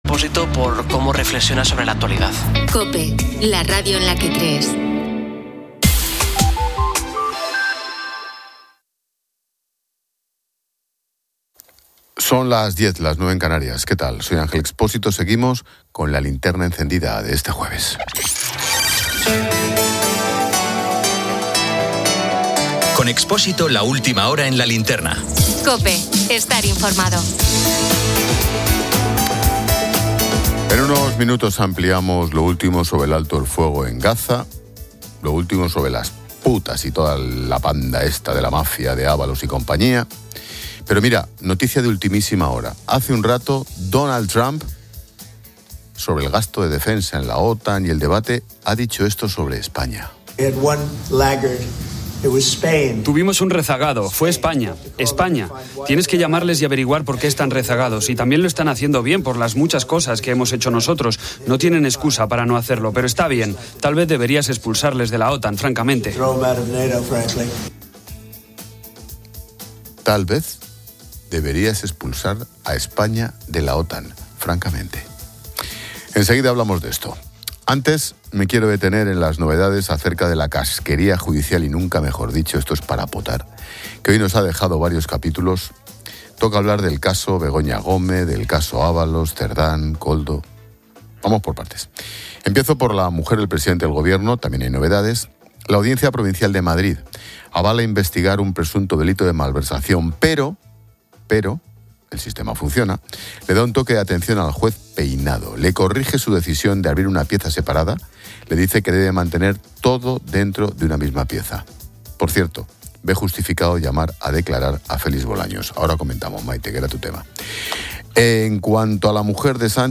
Ángel Expósito informa sobre las declaraciones de Donald Trump, quien sugiere expulsar a España de la OTAN por no cumplir con el gasto en defensa.